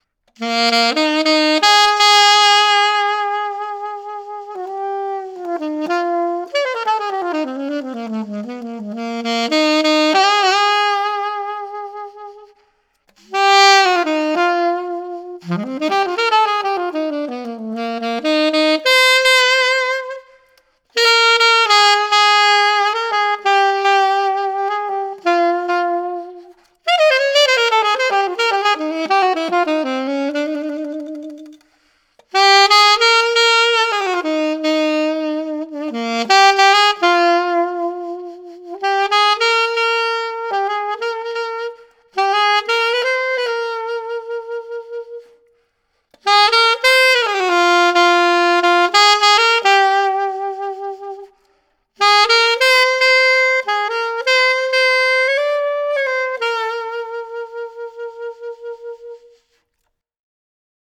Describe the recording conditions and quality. with reverb added Melody with Reverb Added